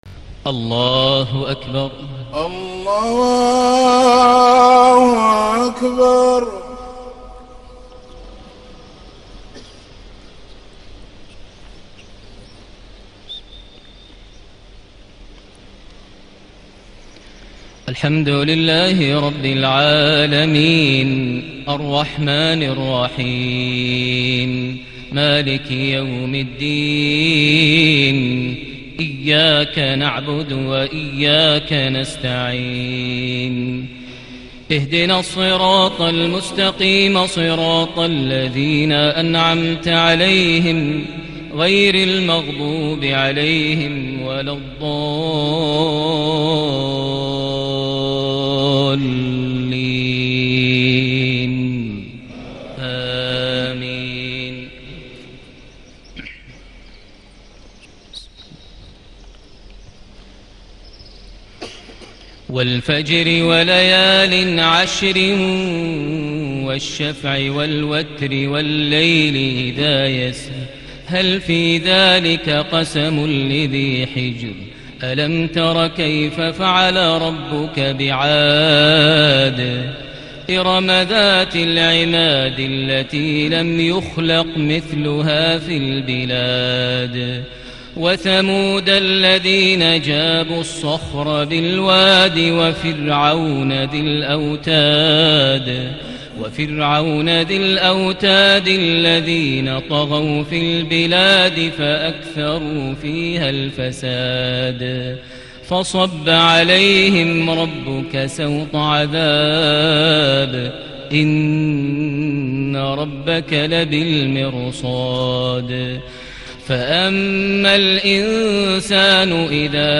صلاة المغرب ٥صفر ١٤٣٨هـ سورة الفجر > 1438 هـ > الفروض - تلاوات ماهر المعيقلي